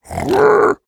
Minecraft Version Minecraft Version 25w18a Latest Release | Latest Snapshot 25w18a / assets / minecraft / sounds / mob / piglin / retreat3.ogg Compare With Compare With Latest Release | Latest Snapshot
retreat3.ogg